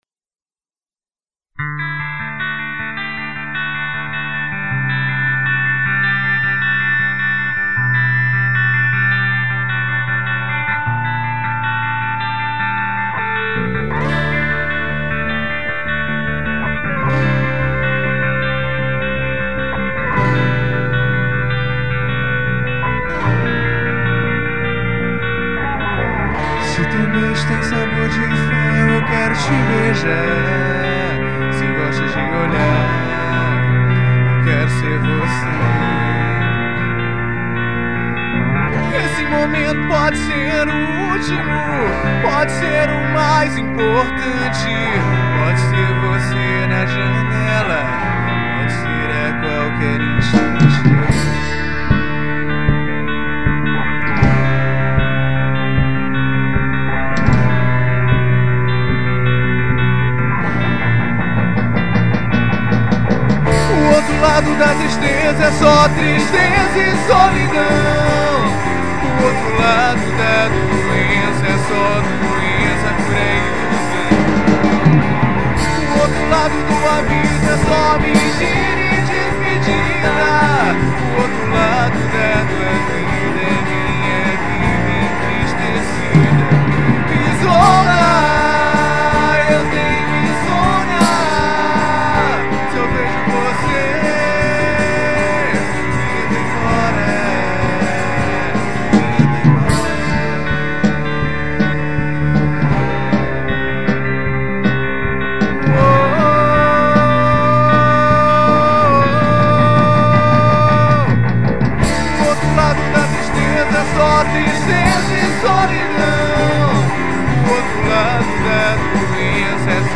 vocal
bateria